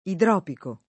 vai all'elenco alfabetico delle voci ingrandisci il carattere 100% rimpicciolisci il carattere stampa invia tramite posta elettronica codividi su Facebook idropico [ idr 0 piko ] agg. e s. m.; pl. m. ‑ci — cfr. ritruopico